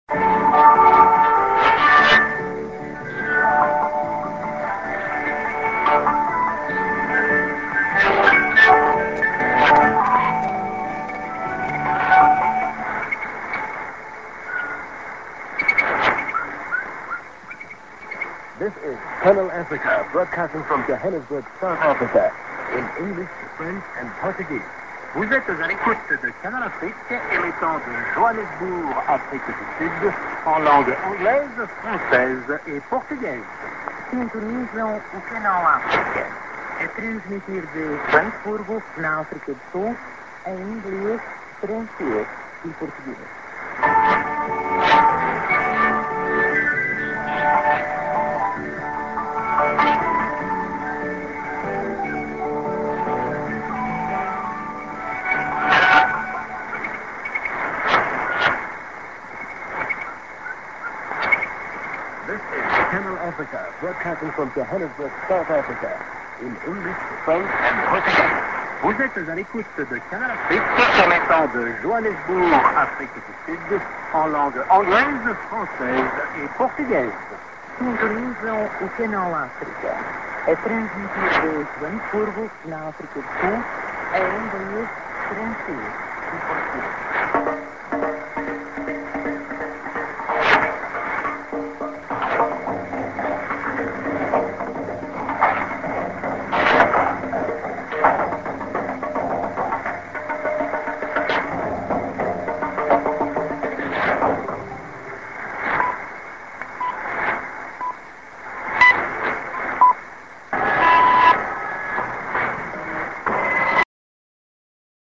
St. IS+ID(man+man+women)->01'26":ST(duram)->TS-> New Style